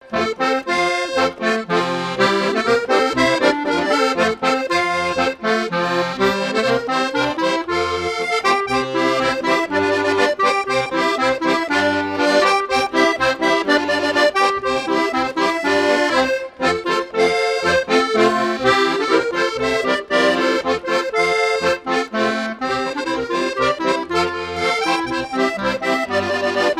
Chants brefs - A danser
danse : polka
Pièce musicale éditée